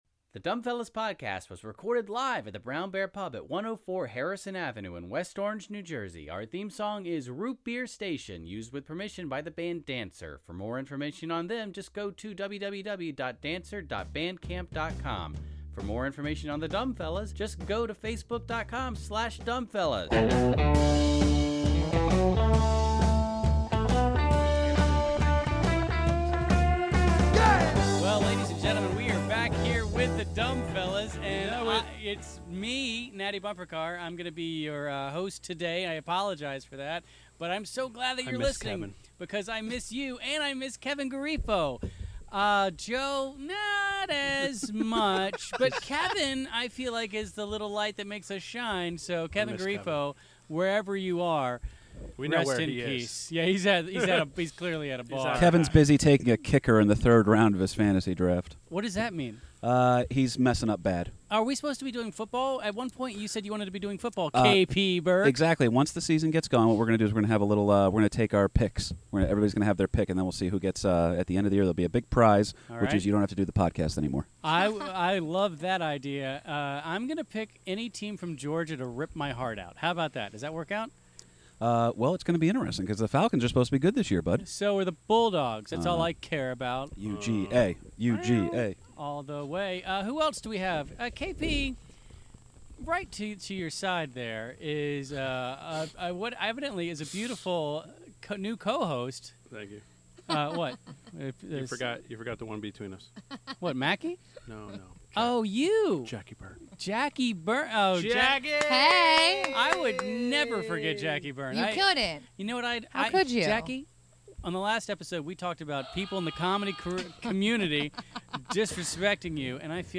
Featuring 5 comedians from New Jersey...